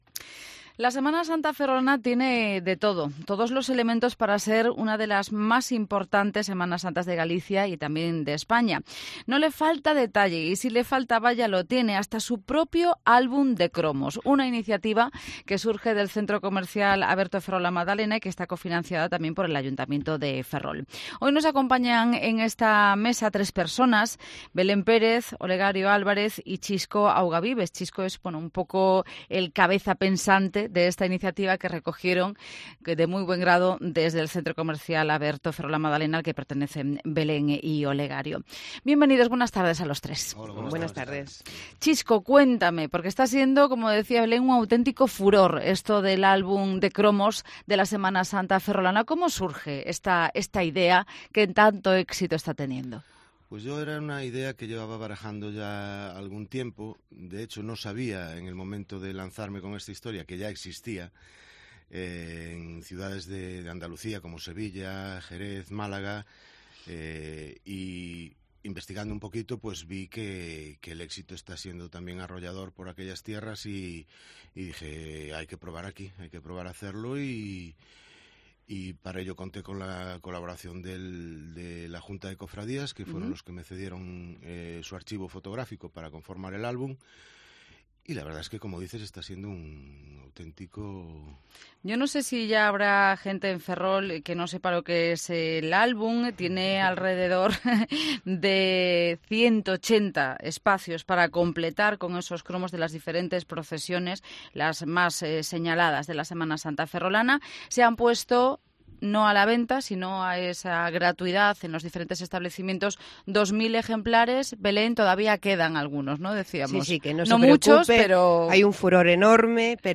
en los estudios de COPE Ferrol